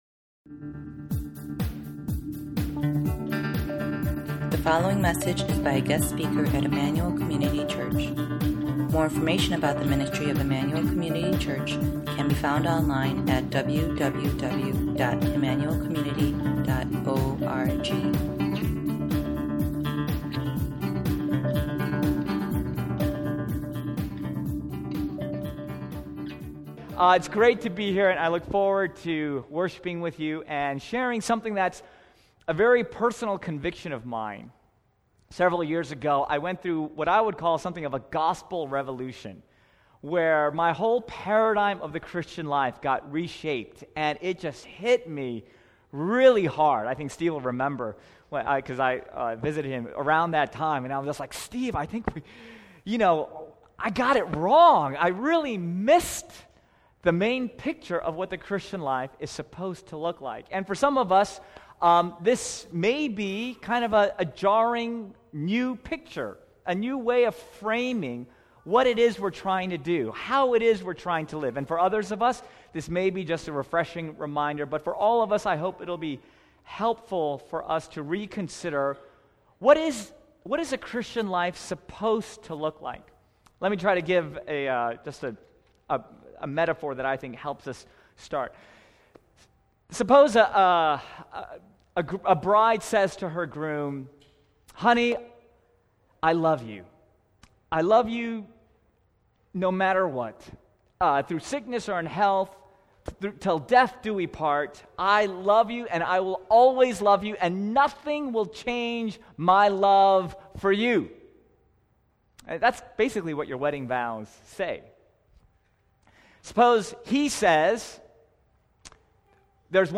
This is the first message from the 2013 ICC Retreat.